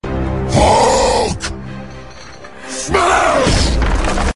Download Free Hulk Smash Sound Effects